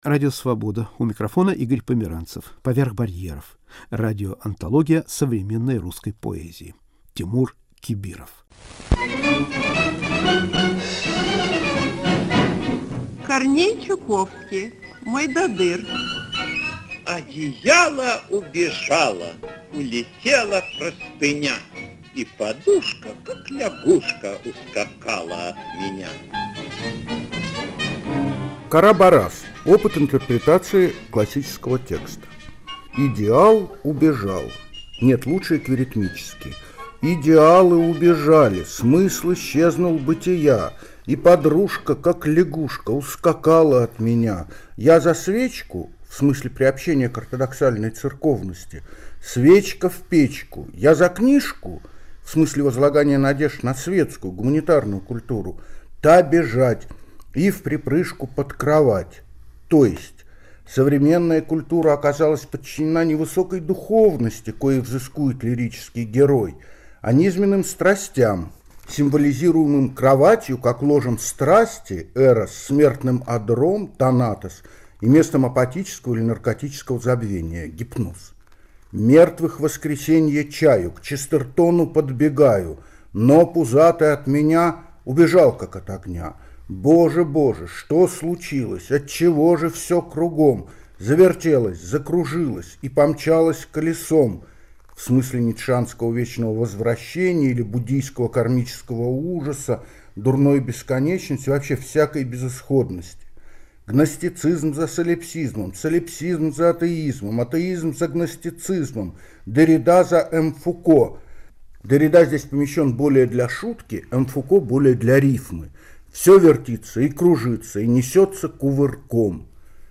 Радиокомпозиция по мотивам поэмы Тимура Кибирова "Кара-Барас!"